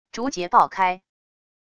竹节爆开wav音频